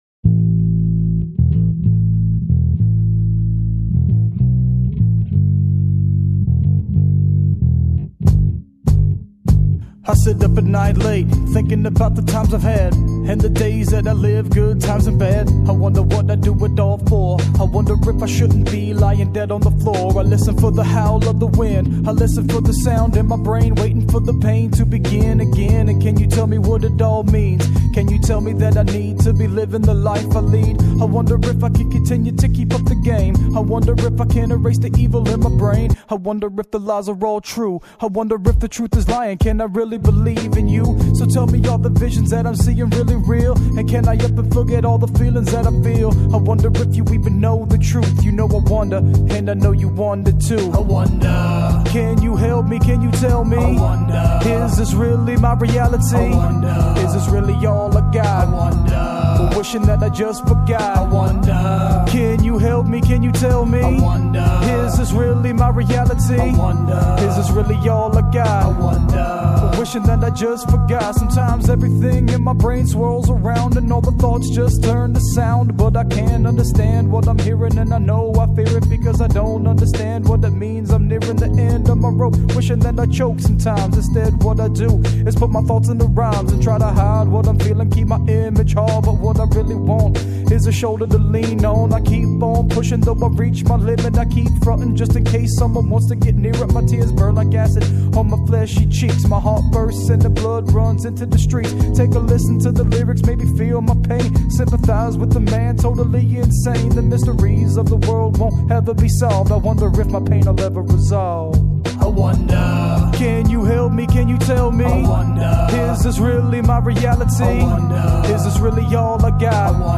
Mid tempo Ml vx, keys Reflecting, sad